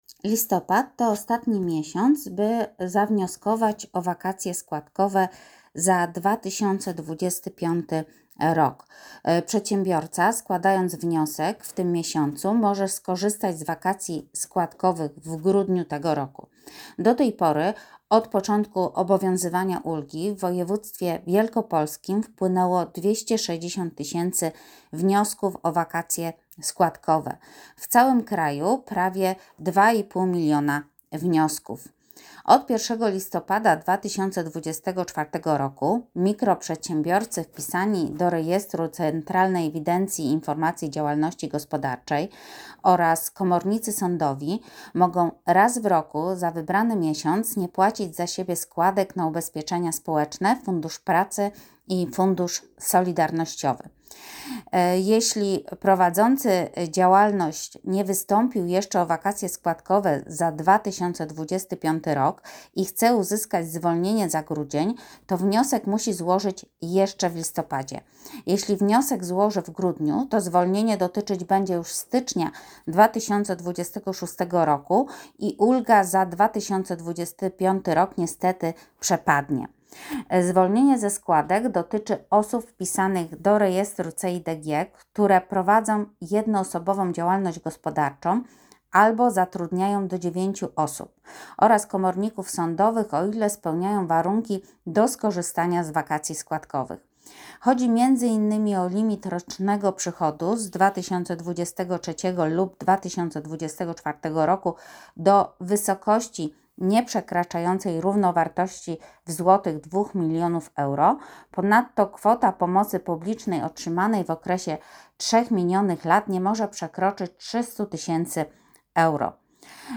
Nagranie głosowe informacji prasowej o Wakacjach składkowych [3.47 MB]